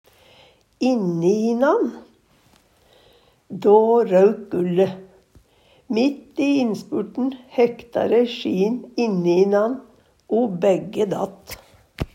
inninan - Numedalsmål (en-US)